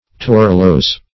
Torulose \Tor"u*lose\, a.